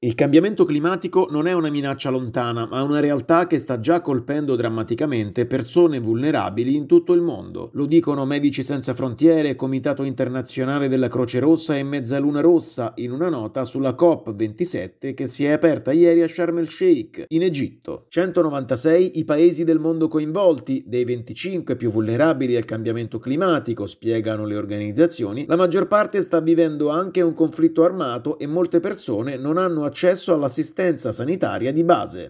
Si è aperta ieri in Egitto la Conferenza dell’Onu sul Clima. Il Servizio